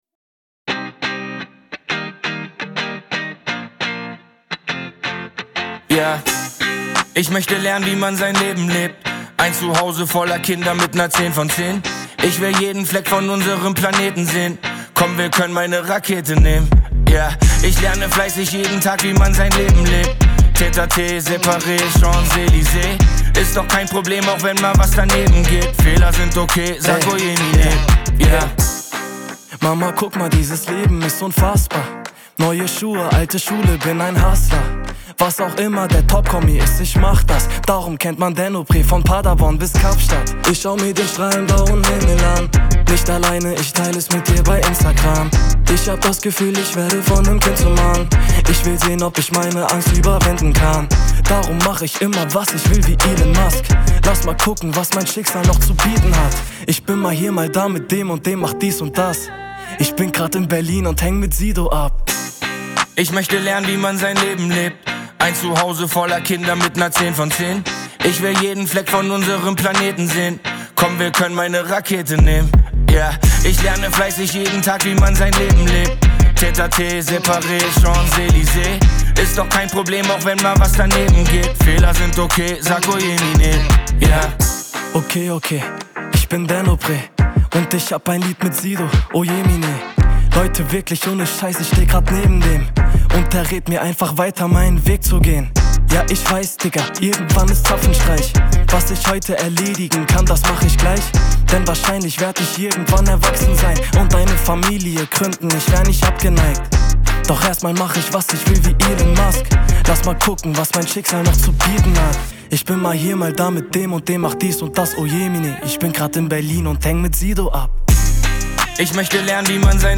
Hip Hop GER